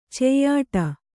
♪ ceyyāṭa